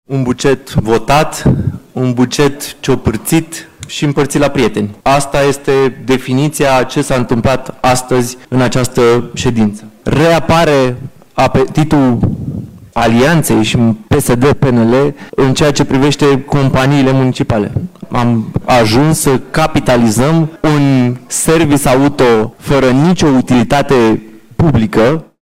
Bugetul Capitalei pentru 2025 a fost aprobat de Consiliul General după o ședință de 5 ore.
Consilierul general USR din opoziție, Radu Dragoș, a contestat majorarea de capital pentru Compania Eco Igienizare, care se ocupă de dezinsecție și deratizare în București.